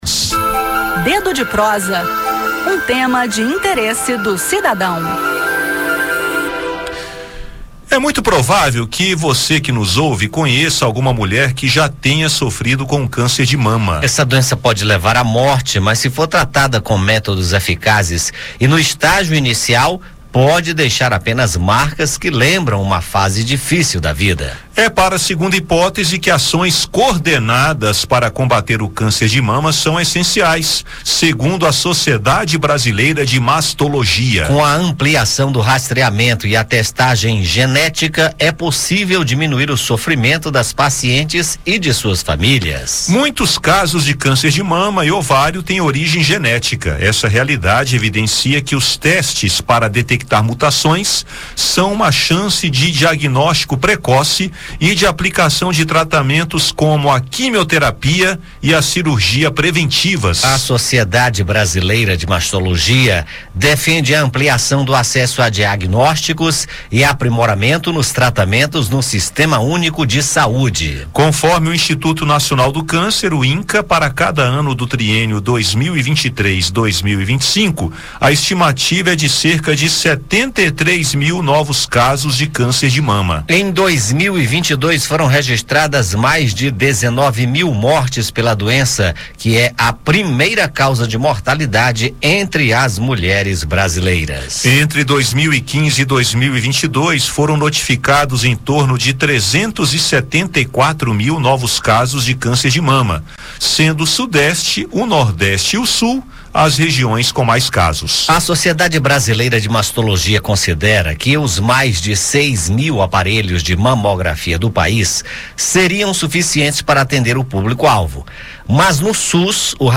A Sociedade Brasileira de Mastologia chama a atenção para essa segunda hipótese, aponta a necessidade de ações coordenadas de combate ao câncer de mama e defende a ampliação do acesso a diagnósticos e aprimoramento nos tratamentos no SUS. Ouça o bate-papo para saber mais.